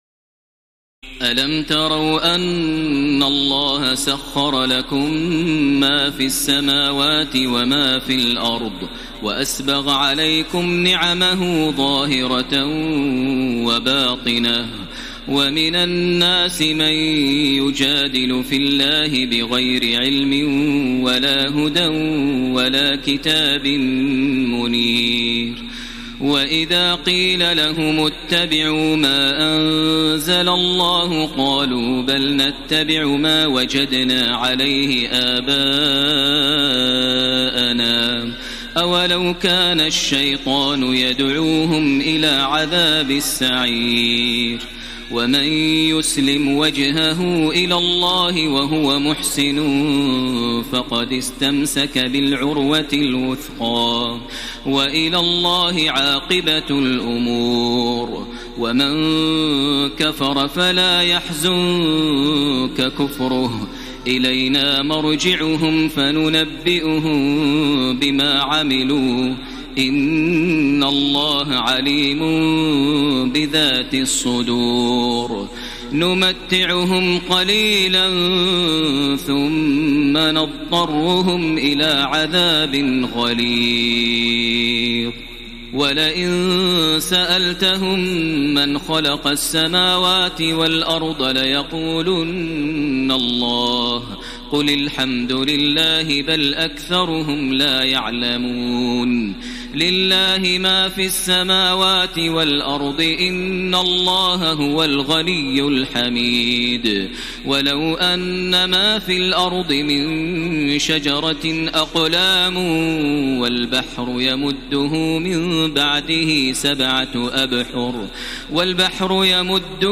تراويح الليلة العشرون رمضان 1433هـ من سور لقمان (20-34) والسجدة و الأحزاب (1-27) Taraweeh 20 st night Ramadan 1433H from Surah Luqman and As-Sajda and Al-Ahzaab > تراويح الحرم المكي عام 1433 🕋 > التراويح - تلاوات الحرمين